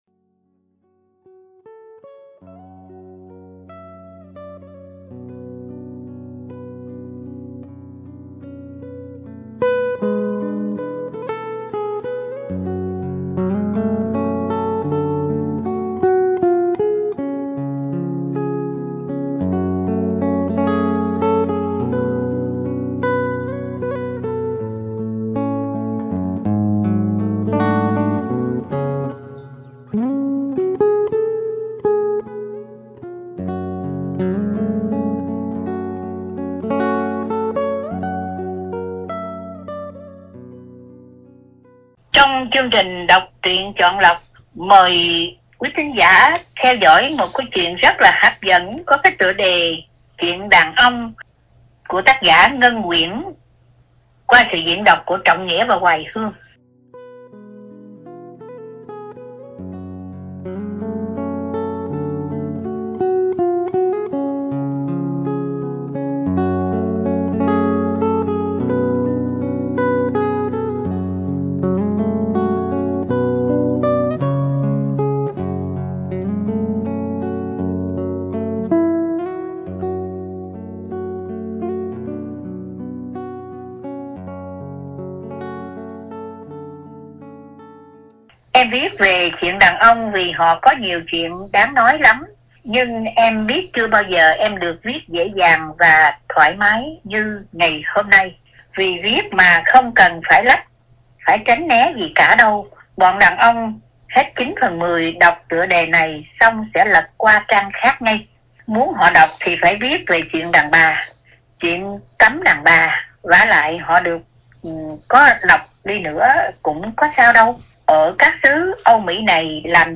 Đọc Truyện Chọn Lọc – Truyện Ngắn “Chuyện Đàn Ông ” – Tác Giả Ngân Uyễn – Radio Tiếng Nước Tôi San Diego